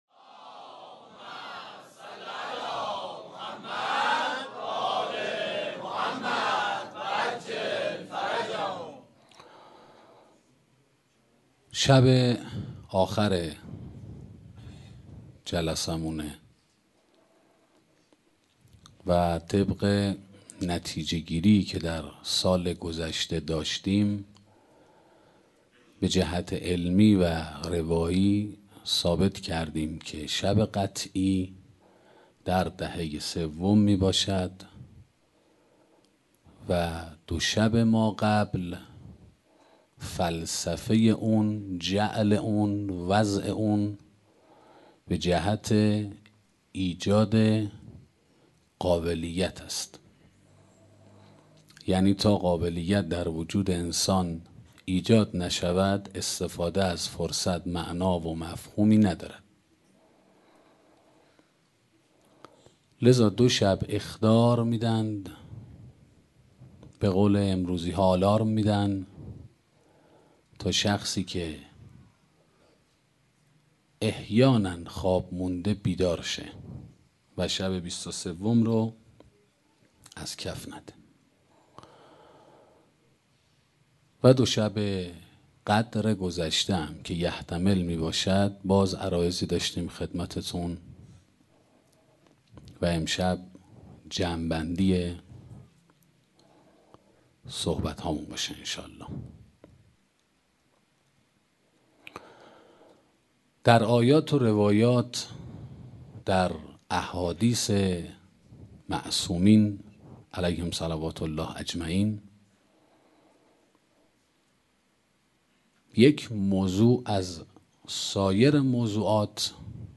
سخنرانی محبت و مودت - موسسه مودت